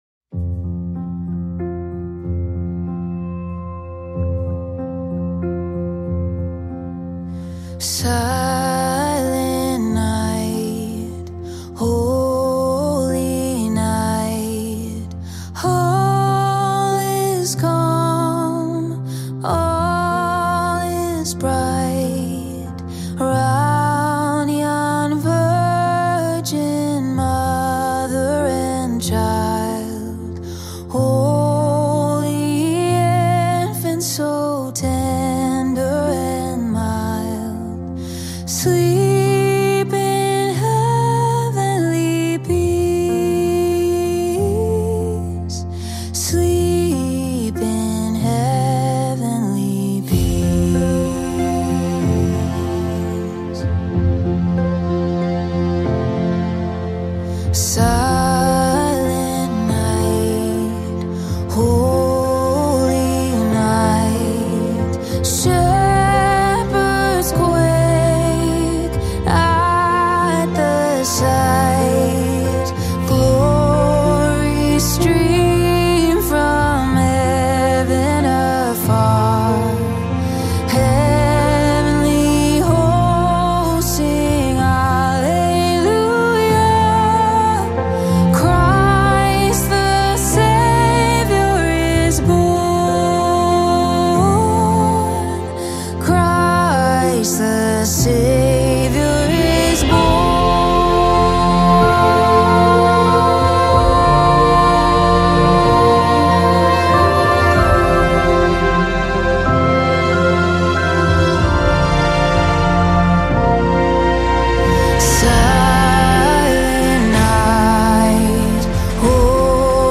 soothing and worshipful rendition